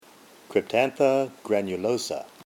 Pronunciation/Pronunciación:
Cryp-tán-tha gra-nu-lò-sa